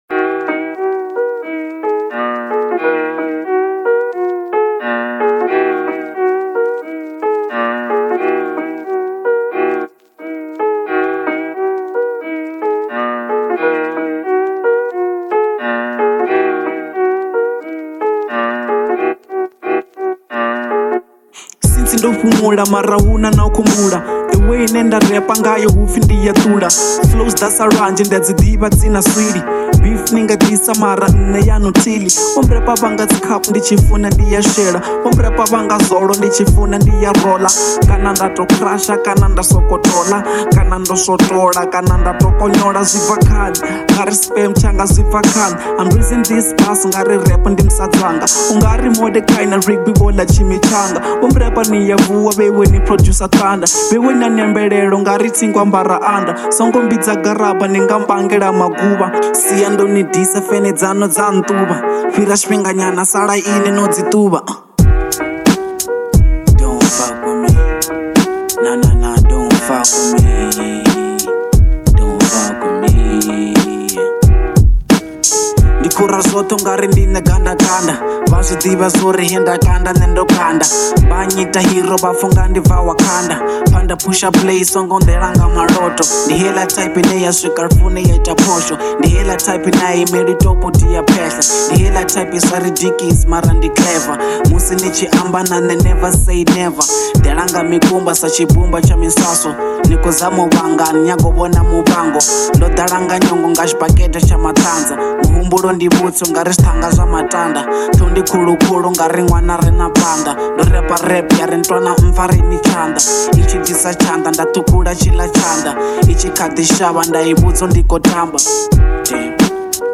Genre : Venrap